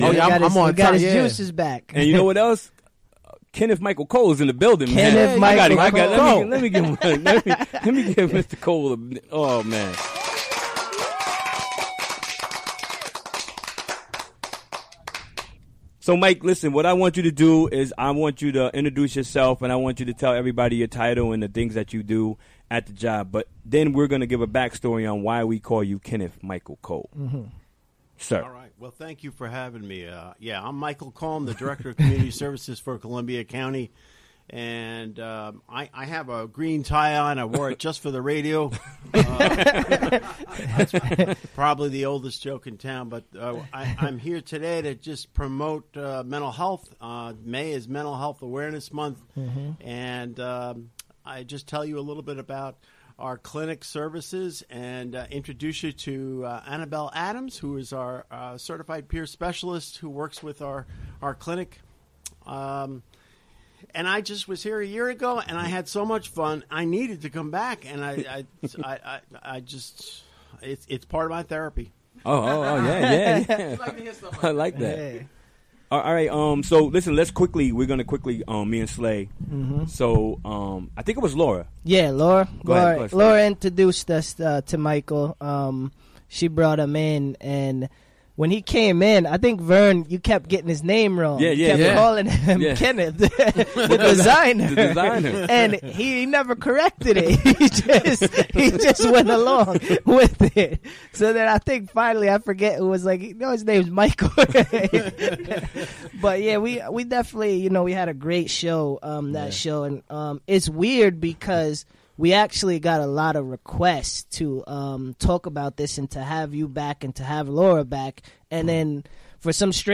Recorded during the WGXC Afternoon Show Wednesday, May 10, 2017.